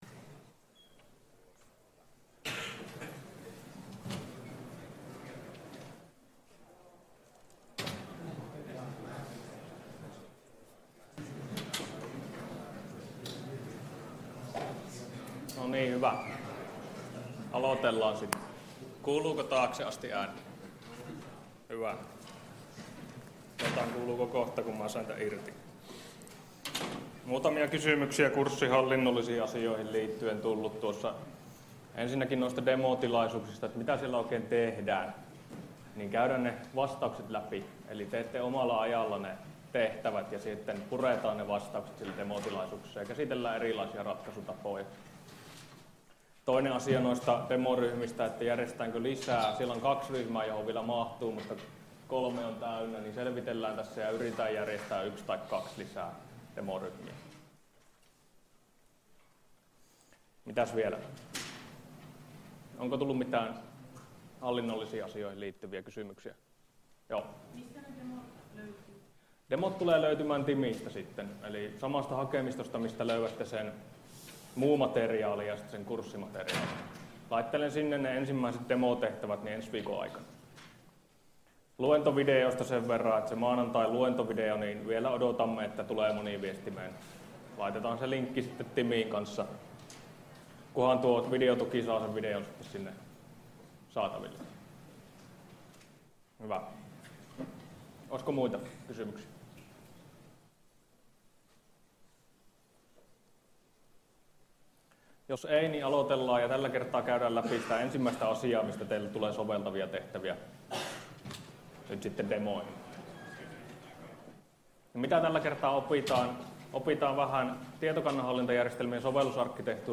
Luento 2 — Moniviestin